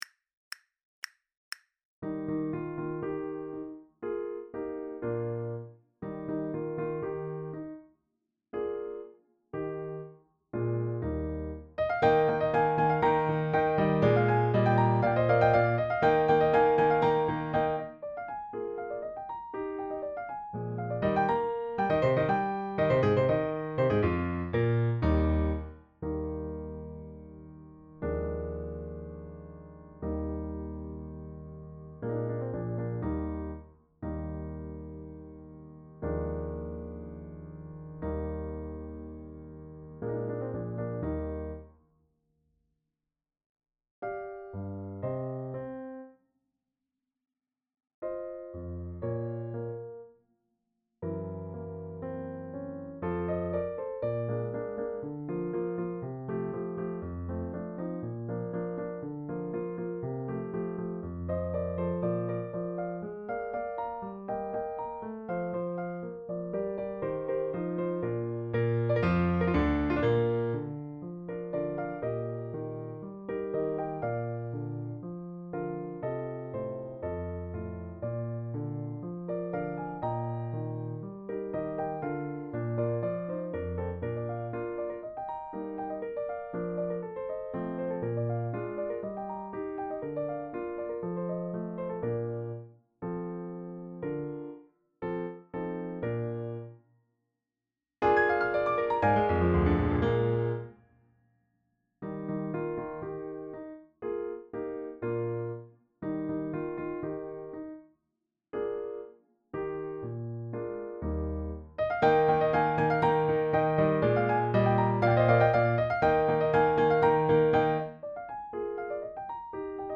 Voicing: French Horn and Piano